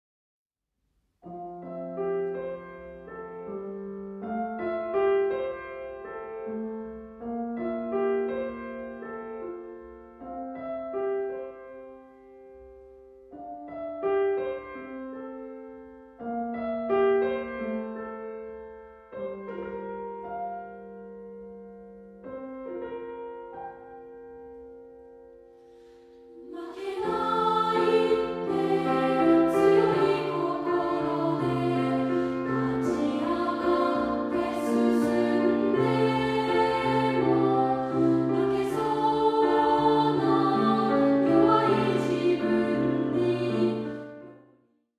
範唱＋カラピアノCD付き
2部合唱／伴奏：ピアノ